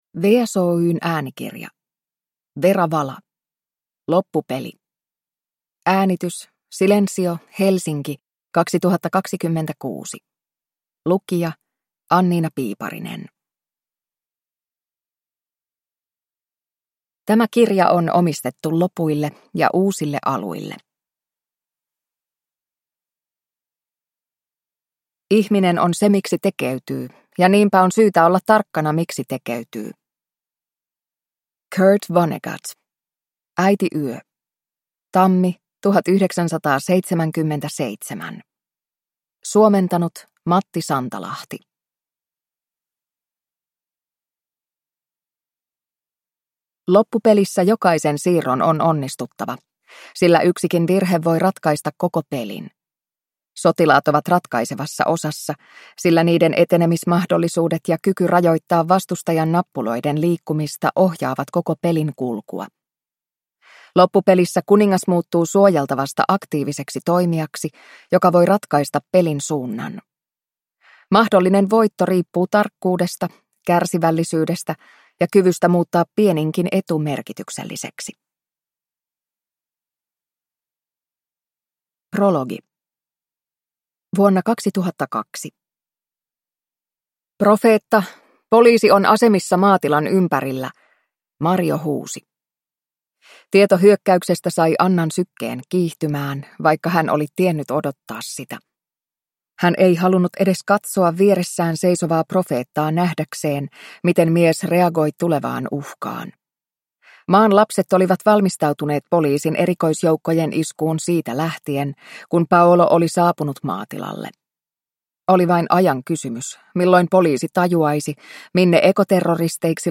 Loppupeli – Ljudbok